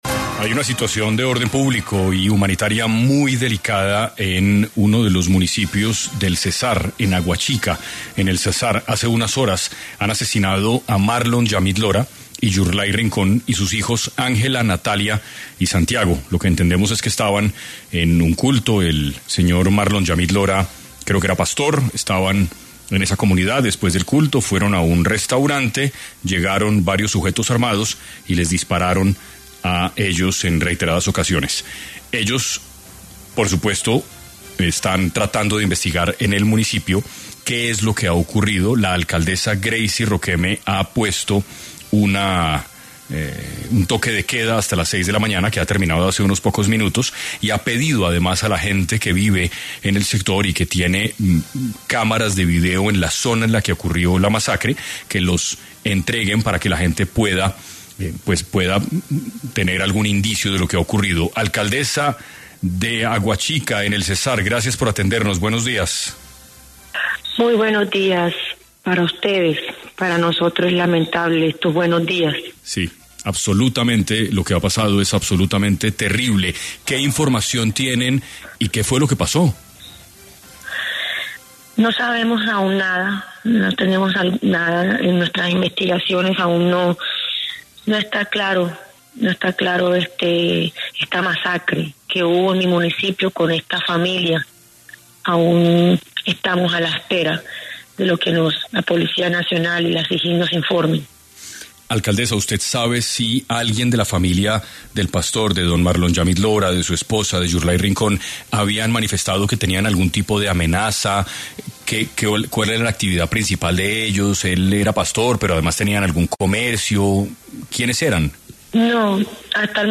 En 6AM de Caracol Radio estuvo la alcaldesa de Aguachica, Greisy Romeque, quien manifestó su tristeza tras la masacre a familia de pastores evangélicos presentada en el municipio.
La alcaldesa del municipio, Greisy Romeque, estuvo en el noticiero 6AM de Caracol Radio y solicitó ayuda al Gobierno Nacional para fortalecer la seguridad en Aguachica, además lamentó desconocer las razones por las que se llevó a cabo esta masacre.